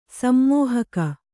♪ sammōhaka